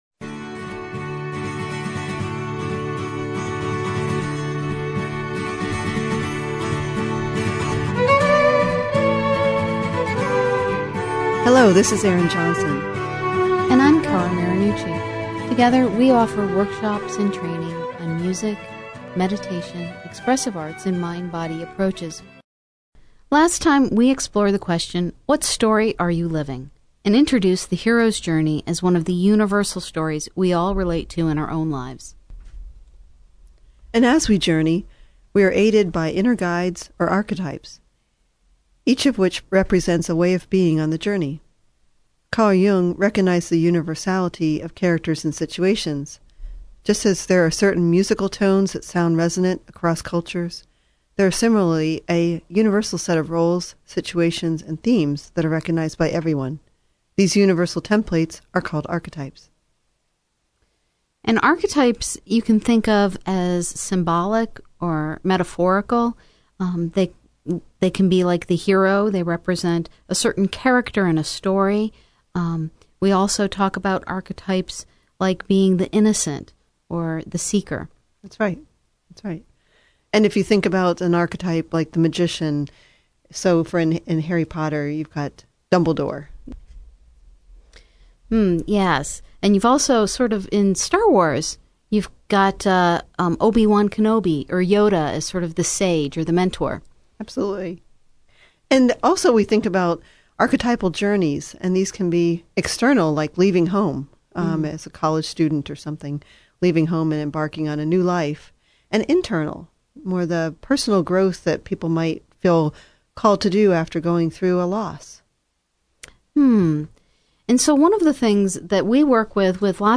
We will invite you into our conversation on various meditation practices and how sound and music can be used to support your meditation practice.